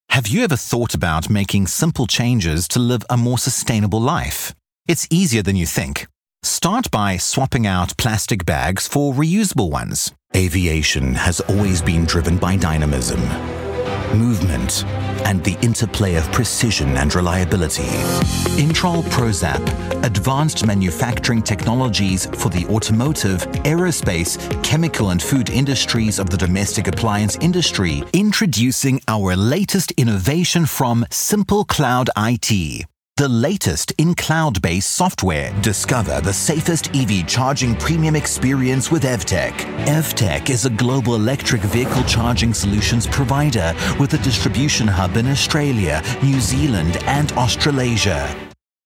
Vídeos corporativos
Cálido, fluido y versátil. Experimentado y atractivo.
Apogee Mic+